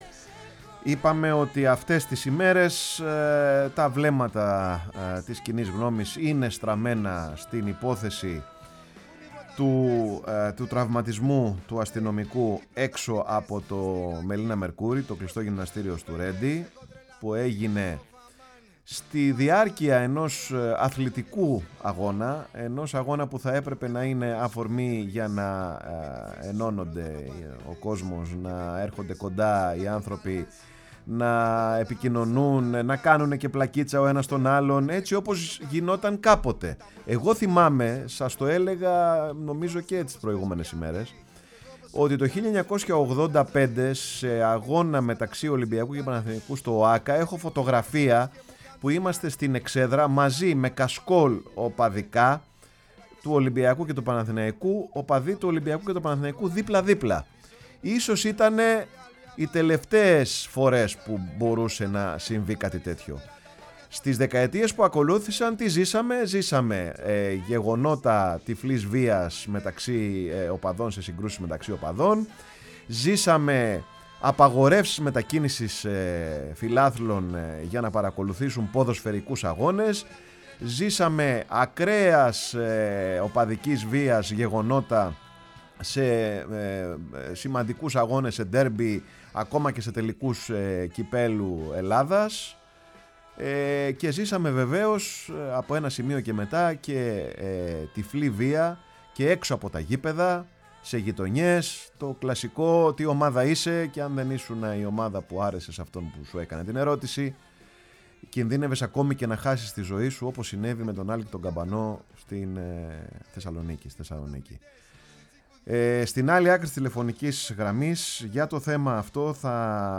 Η ΦΩΝΗ ΤΗΣ ΕΛΛΑΔΑΣ Παρε τον Χρονο σου ΣΥΝΕΝΤΕΥΞΕΙΣ Συνεντεύξεις Αντωνης Αντωνιαδης